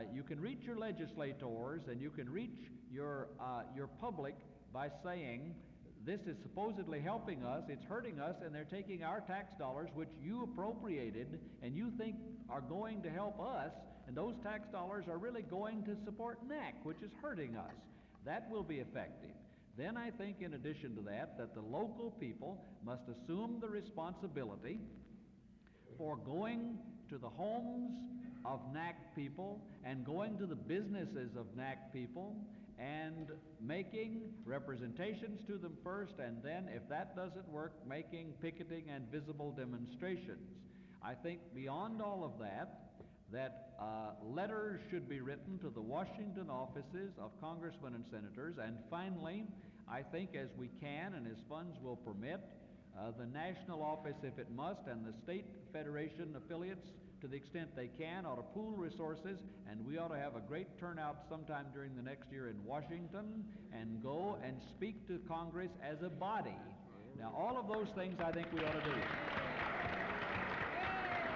Comments from Kenneth Jernigan at the July 1973 Executive Committee Meeting | National Federation of the Blind Museum of the Blind People’s Movement